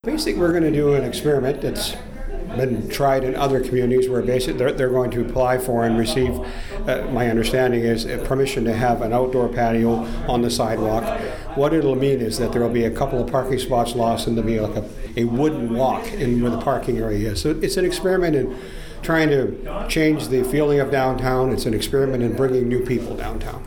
Courtenay Mayor Larry Jangula says this is something that’s been successful in other communities.